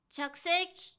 ダウンロード 女性_「礼」
女性挨拶